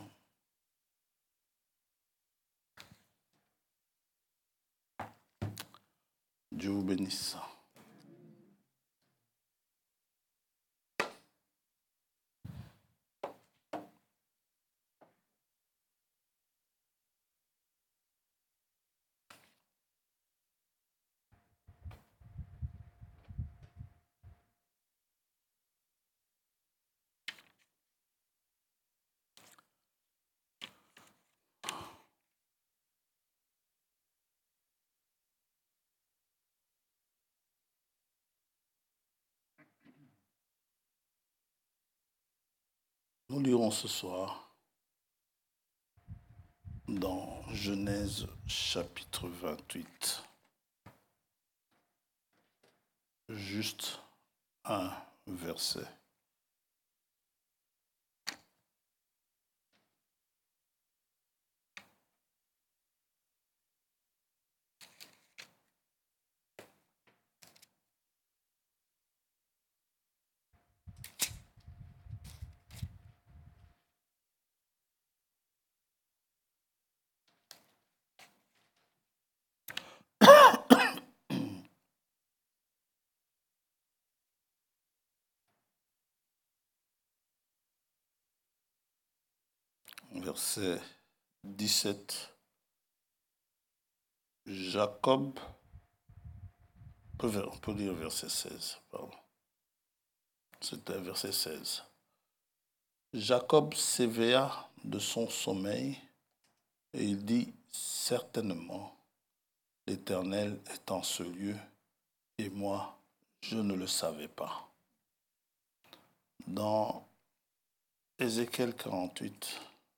Catégorie: Prédications